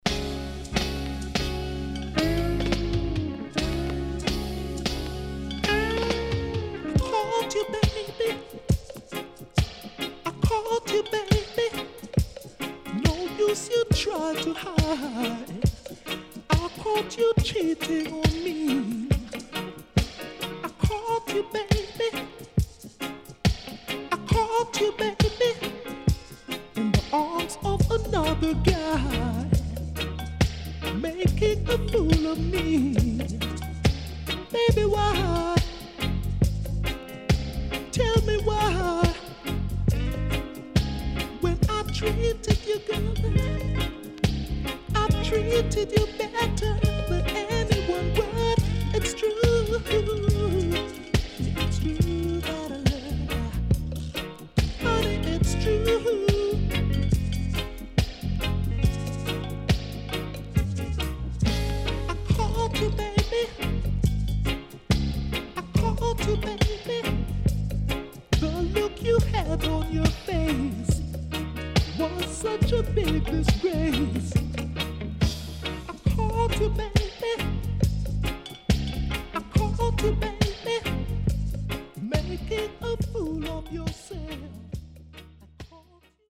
【12inch】
SIDE A:所々チリノイズ入ります。